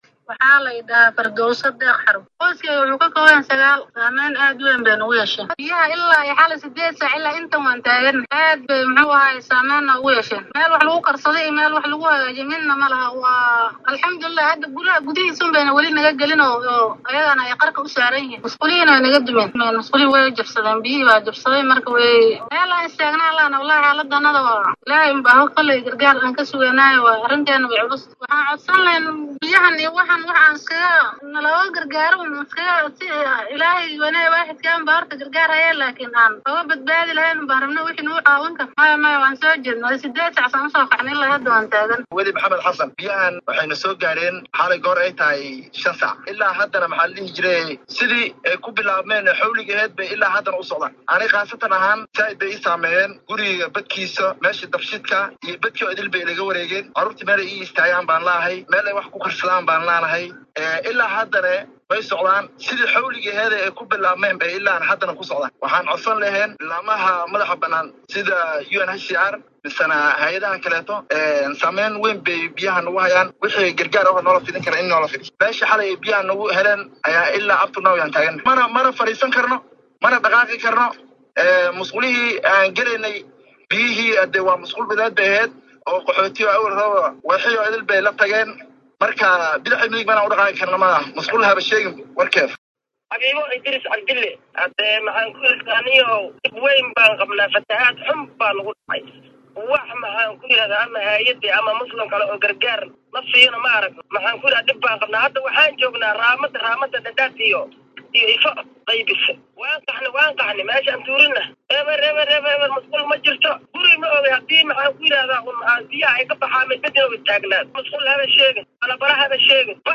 DHAGEYSO:Qaar ka mid ah dadka ay saameeyeen daadadka IFO oo warbaahinta Star u warramay
Waxaa dumay musqullo badan halka wasaqdii musqullada iyo biyaha ay isku darsameen . Qaar ka mid ah shacabka iyo mas’uuliyiinta xerada IFO ayaa la hadlay warbaahinta Star.
Shacabka-iyo-masuuliyiinta-IFO.mp3